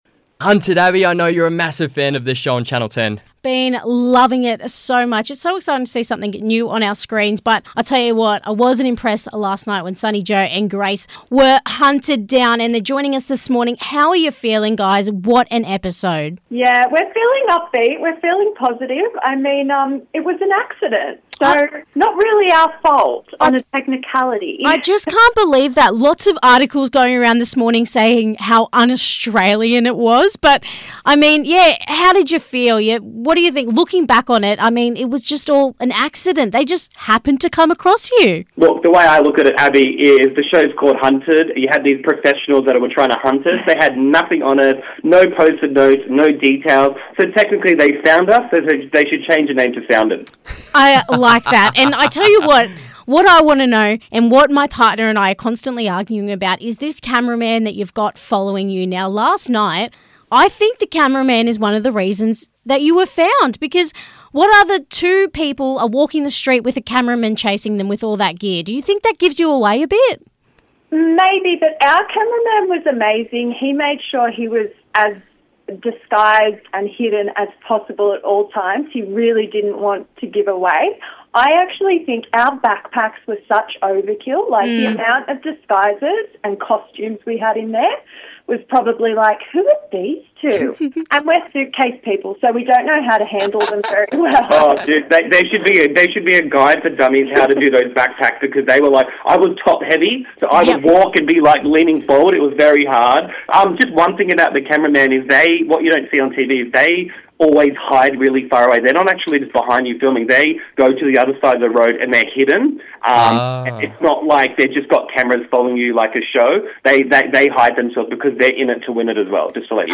Listen to their EXTENDED chat now!